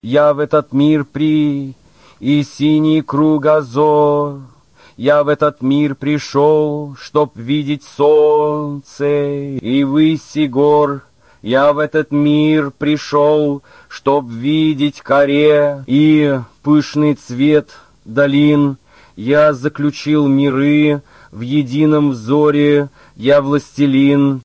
JosephBrodsky_Fast.mp3